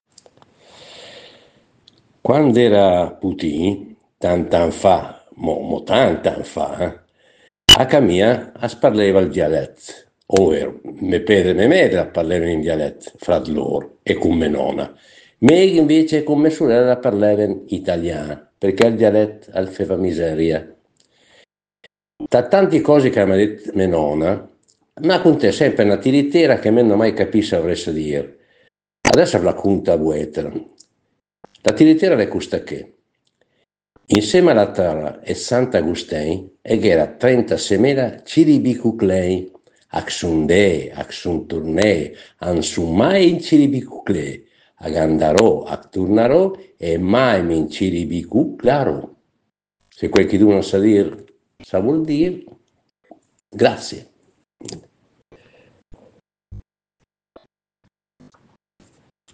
Tuttavia, in seconda istanza, abbiamo anche sfruttato le molte conoscenze dirette che abbiamo, come gruppo di Léngua Mêdra, con persone parlanti dialetti ben caratterizzati.